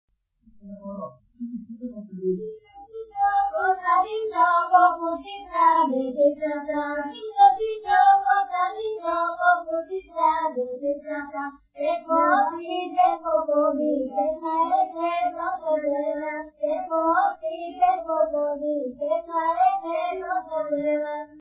музикална класификация Песен
тематика Хумористична
форма Двуделна форма
размер Девет шестнадесети
фактура Едногласна
начин на изпълнение Група (на отпяване)
битова функция На хоро
фолклорна област Средна Западна България
място на записа Бобошево
начин на записване Магнетофонна лента